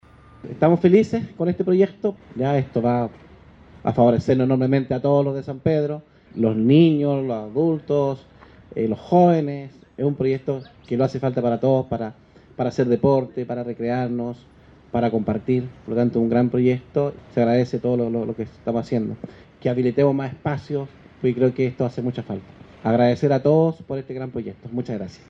En las últimas horas se realizó la ceremonia de inicio de las obras correspondientes al proyecto “Construcción Aéreas Verdes en Parque deportivo y Recreacional” en la comuna de San Pedro, actividad que contó con la presencia de representantes del MINVU y SERVIU, junto al Alcalde de la comuna Emilio Cerda, en compañía de Concejales, dirigentes sociales, vecinos y representantes de la Empresa a cargo de la construcción de este importante espacio en beneficio de la comunidad.
DECLARACION-01-ALCALDE-1.mp3